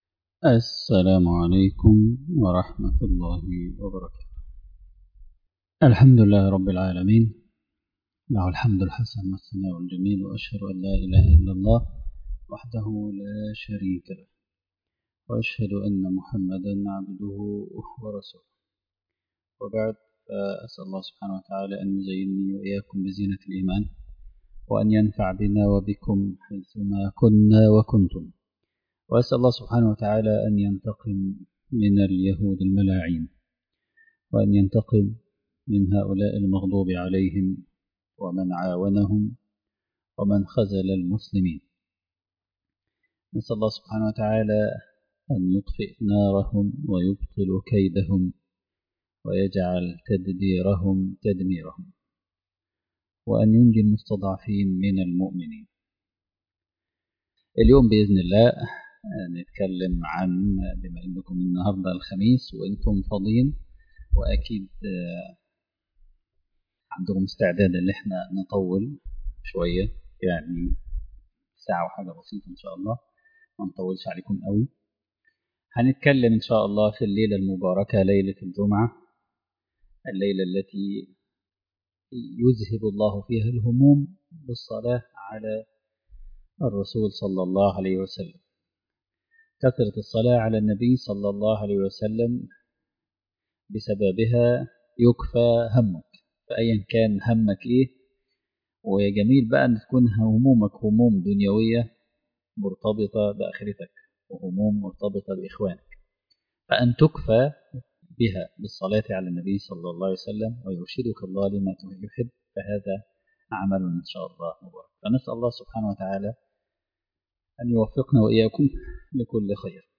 سعي بوعي | المحاضرة التاسعة